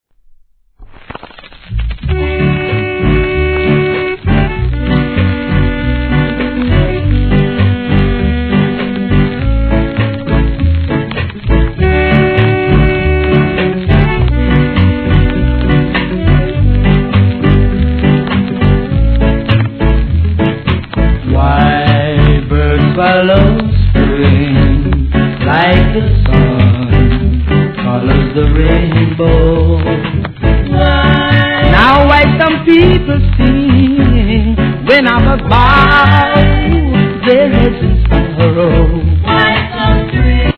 7inch
REGGAE